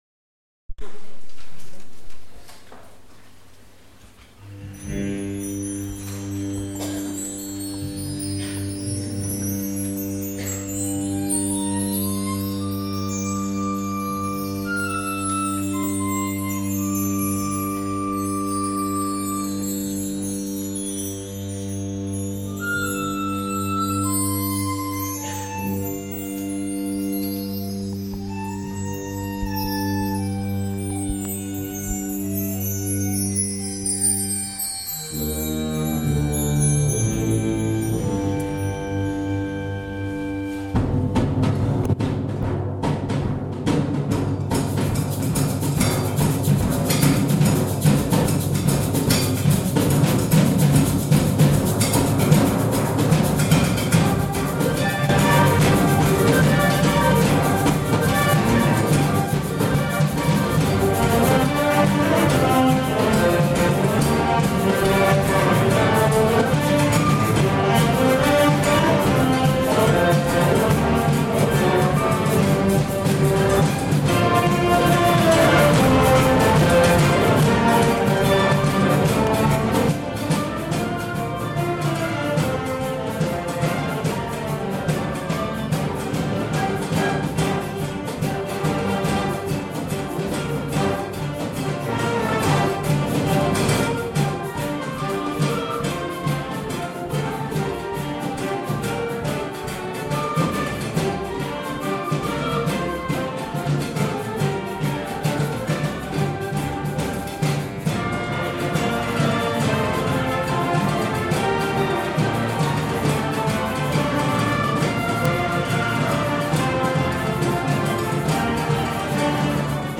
Concert Band - Celtic Carol
A Concert of Wind, Brass and Percussion, April 2015